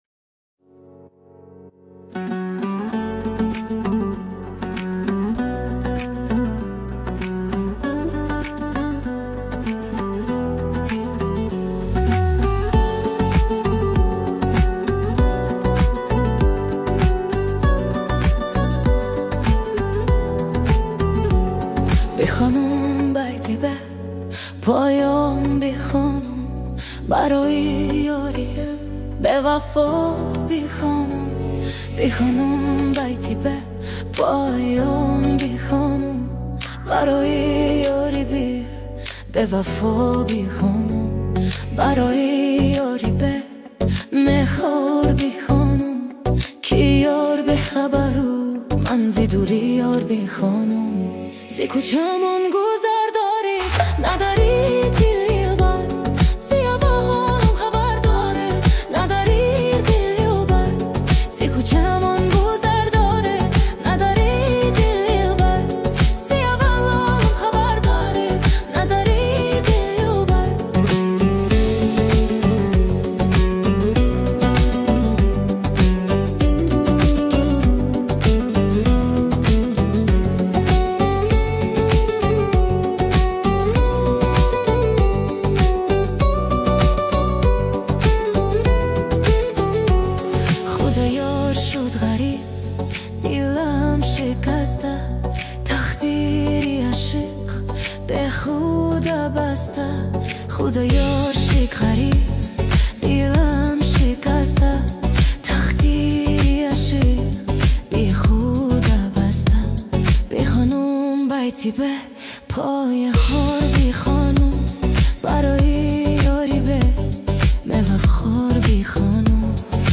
Музыка / 2025-год / 2024- Год / Таджикские / Поп / Прочее